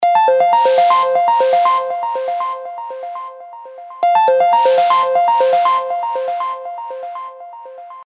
Call_Incoming.8b64401f6a96e97c932e.mp3